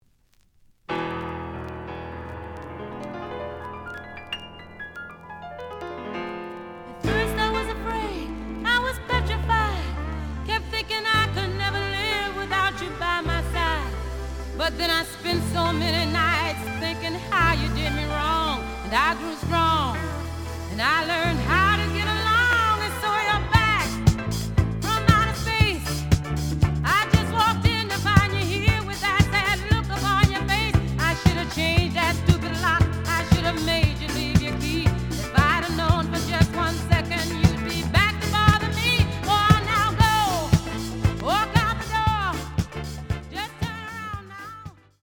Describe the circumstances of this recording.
The audio sample is recorded from the actual item. ●Format: 7 inch Slight edge warp.